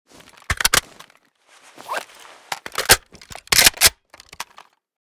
ak74_empty_reload.ogg.bak